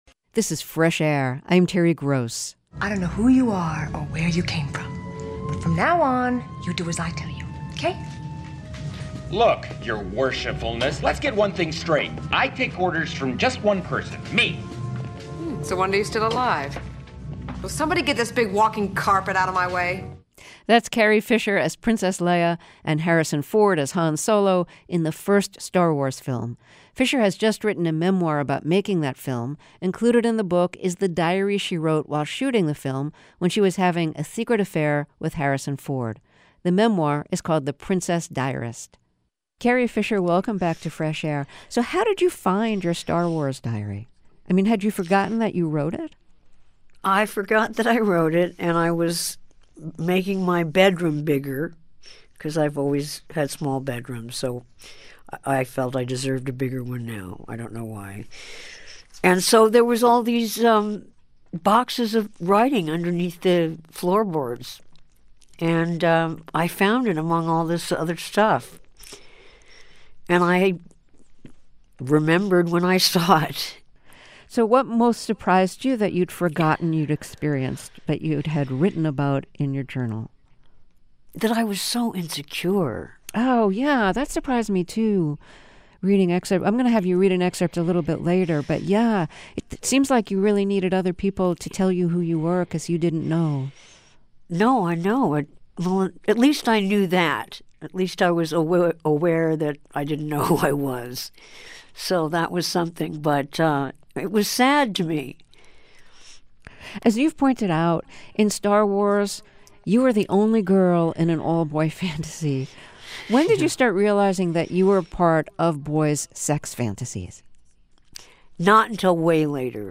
Carrie Fisher – interviewed by Terry Gross for Fresh Air – November 28, 2016 – NPR